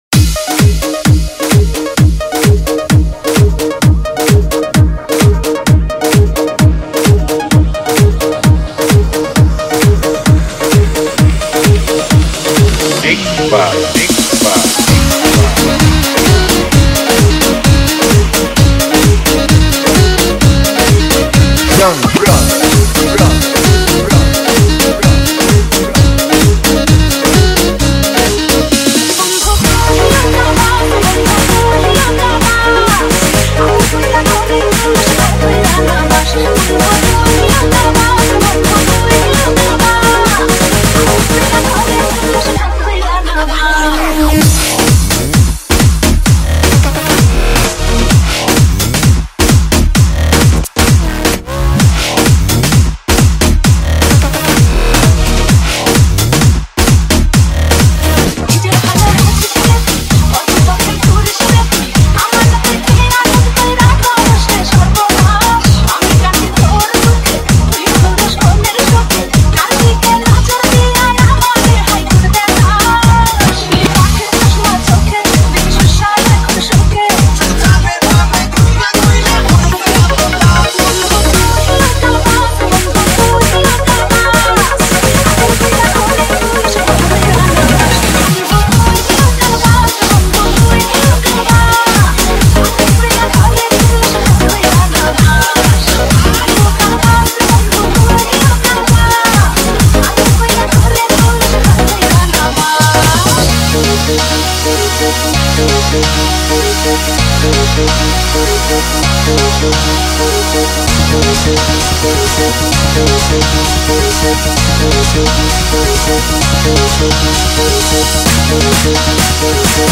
Category : Bangla Remix Song